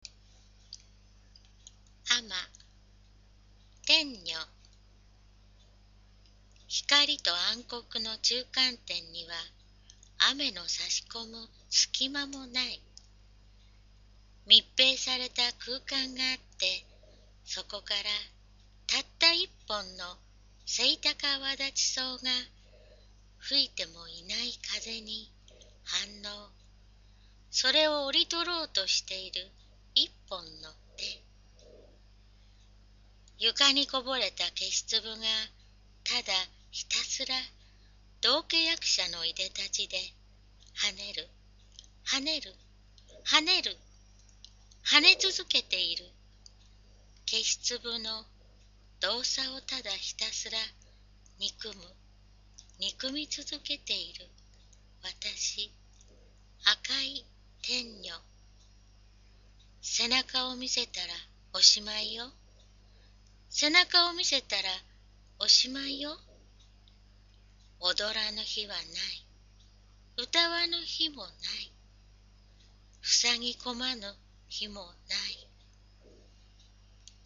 poemreadtennyo001.mp3